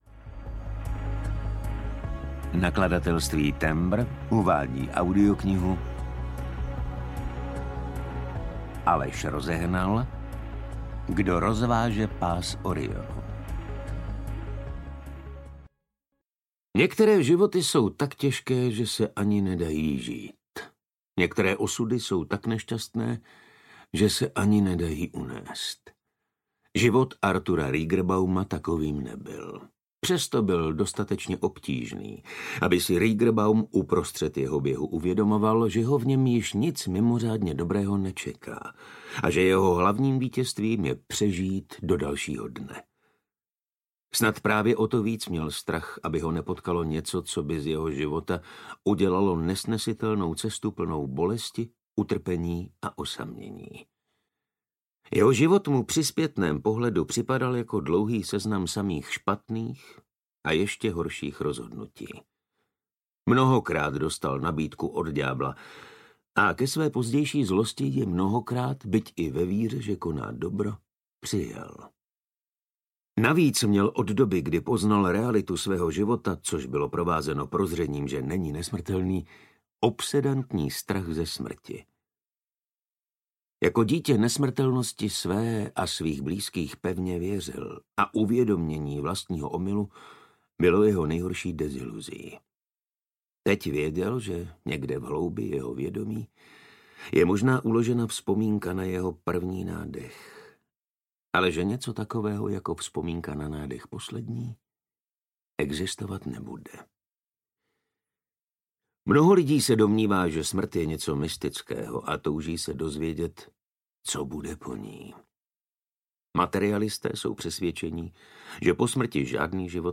Audio knihaKdo rozváže pás Orionu
Ukázka z knihy